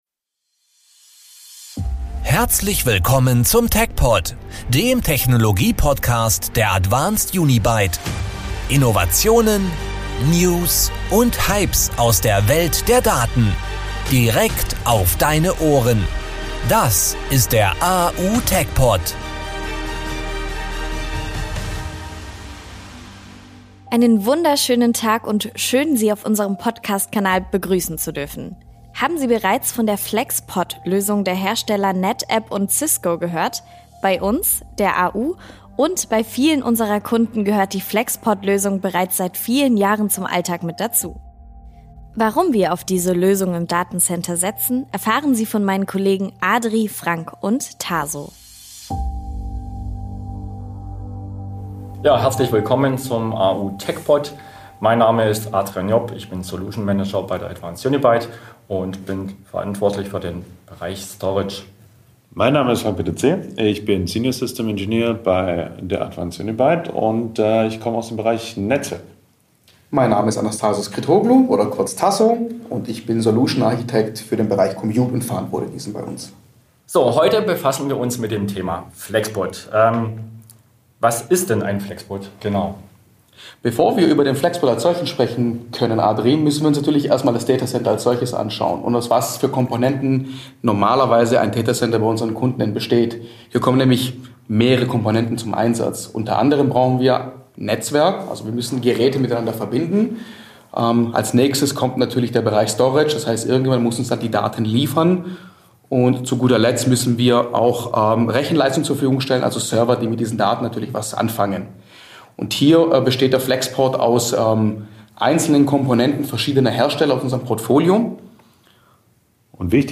Expertengespräch